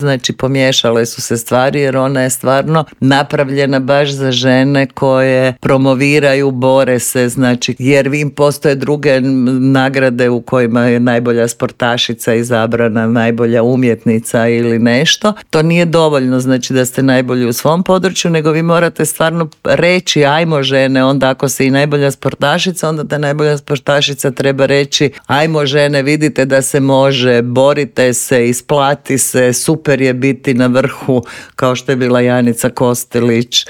Gostujući u Intervjuu Media servisa